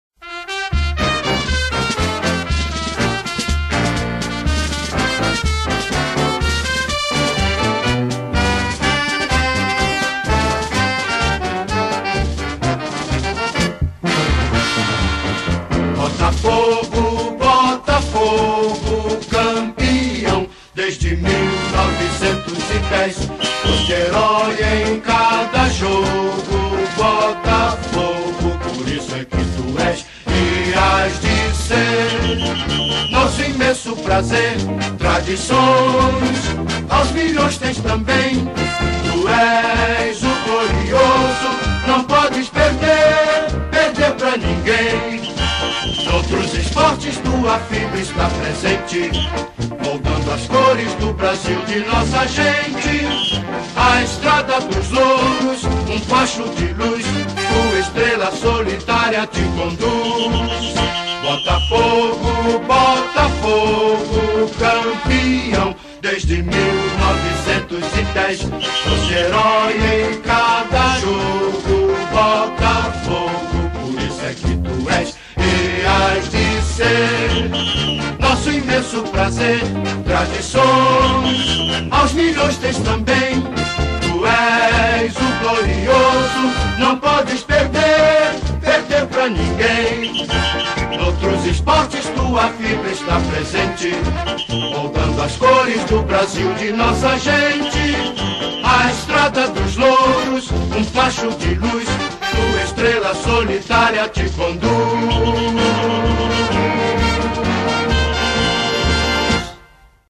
2024-12-17 14:34:40 Gênero: MPB Views